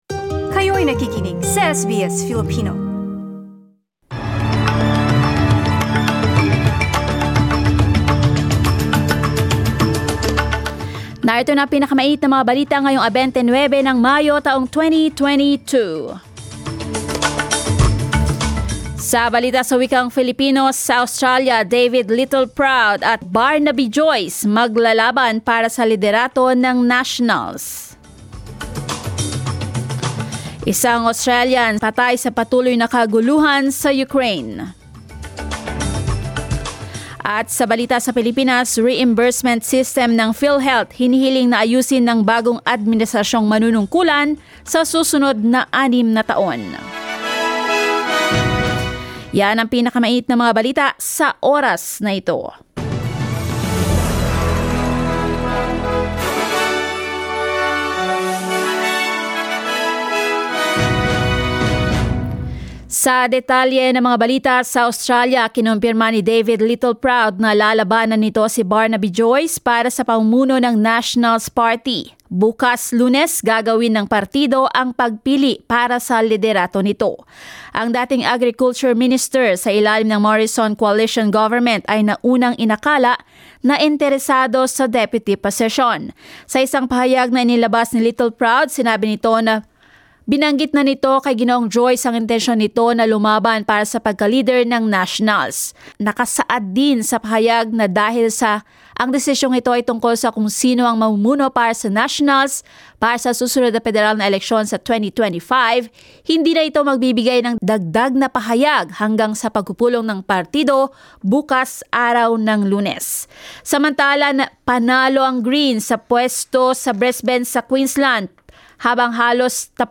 SBS News in Filipino, Sunday 29 May